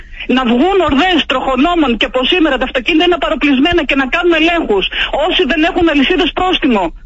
Την ώρα που ολόκληρος ο κρατικός μηχανισμός με την κυβέρνηση βούλιαζε στο χιόνι και η ζωή χιλιάδων ανθρώπων κινδύνευε από την ανικανότητα του επιτελικού… κράτους της κυβέρνησης, αντί συγνώμης, η βουλευτής της Νέας Δημοκρατίας μιλώντας στο ράδιο Ζυγός, ζήτησε την τιμωρία τους… να βγούνε «ορδές τροχονόμων και να κάνουν ελέγχους στα παροπλισμένα αυτοκίνητα που είναι εκεί και να επιβάλει πρόστιμα σε όσους οδηγούς δεν έχουν αλυσίδες».